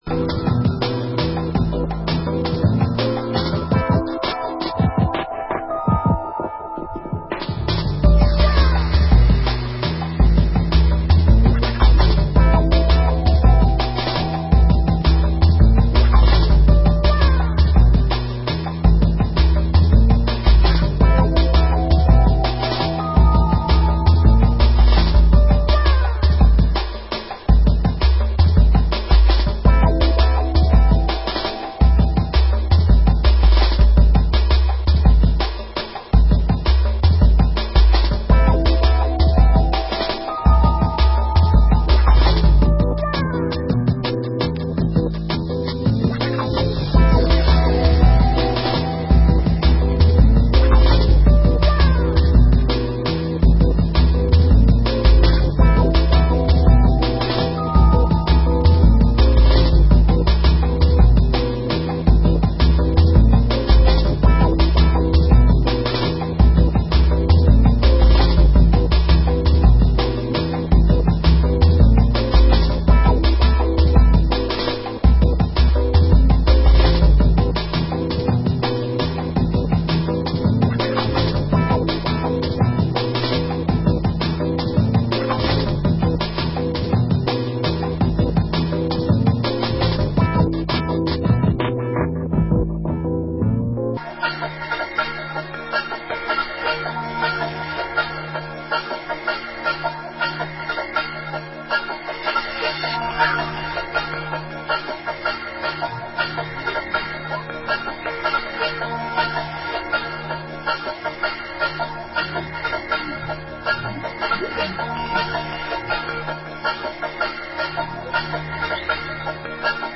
Genre Drum & Bass